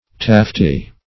Taffeta \Taf"fe*ta\, Taffety \Taf"fe*ty\, n. [F. taffetas, It.